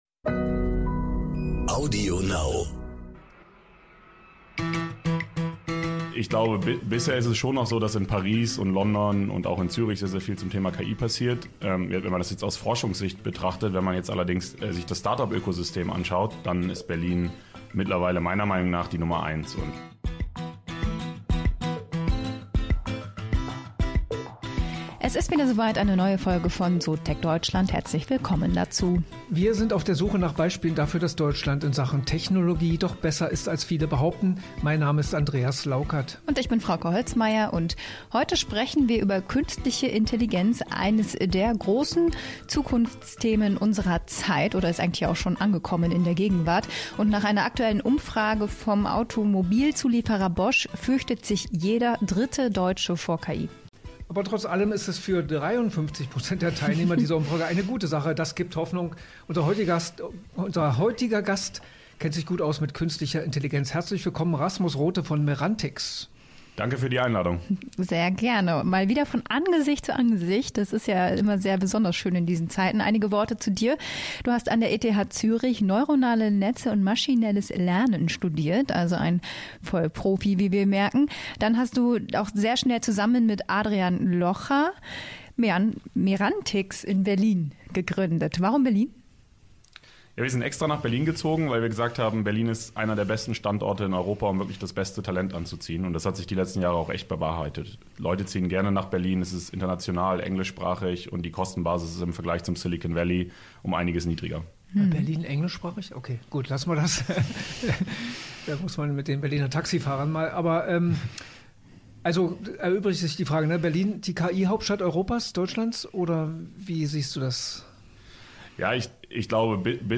Ein Gespräch über Erfolge bei KI-gestützter Brustkrebserkennung, Fördermaßnahmen bei KI und Berlin als KI-Standort.